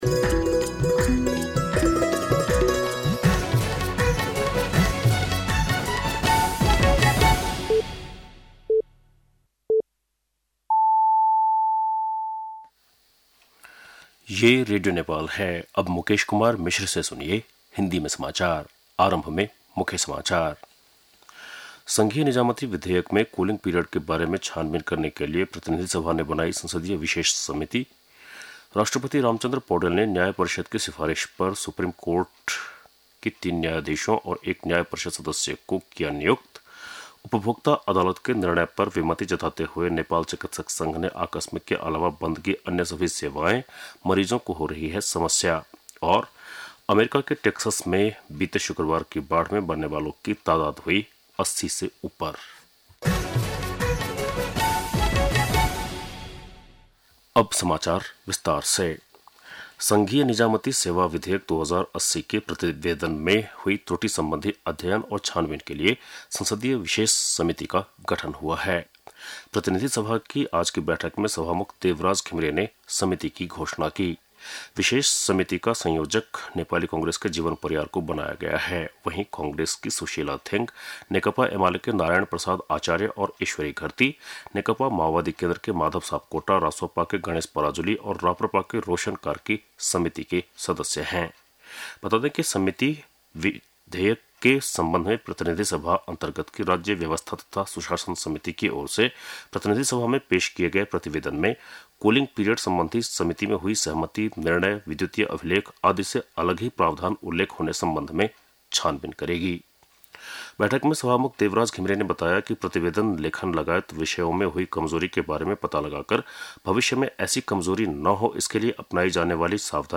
बेलुकी १० बजेको हिन्दी समाचार : २३ असार , २०८२
10-PM-Hindi-NEWS-3-23.mp3